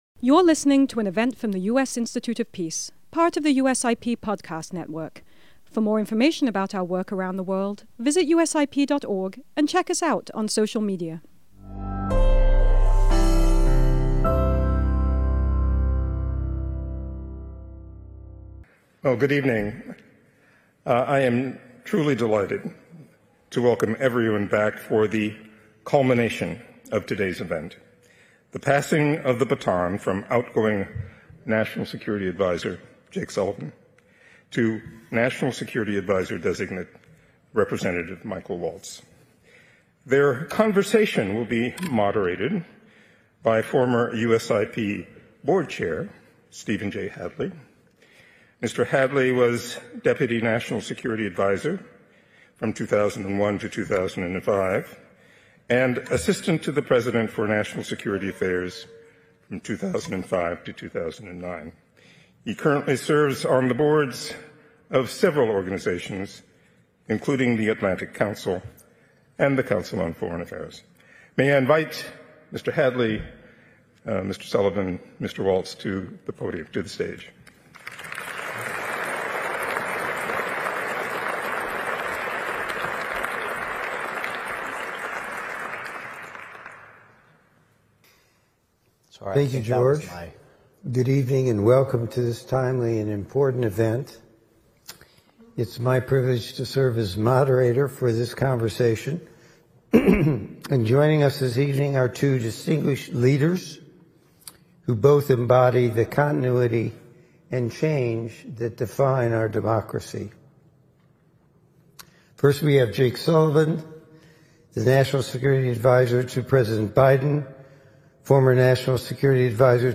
This flagship, bipartisan event brought together national security leaders from across the political spectrum to mark the peaceful transfer of power and the bipartisan character of American foreign policy.